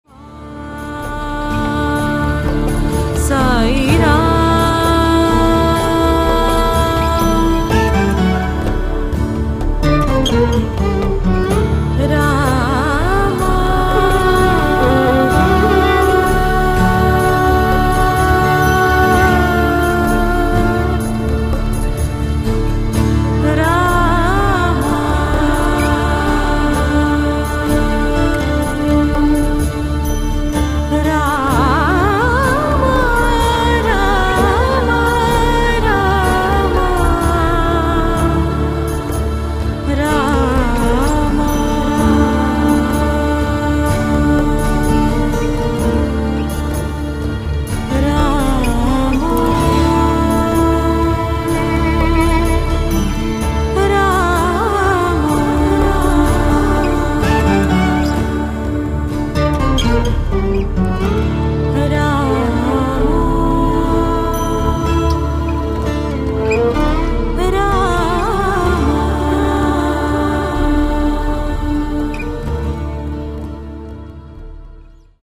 for that usual spaciously clear production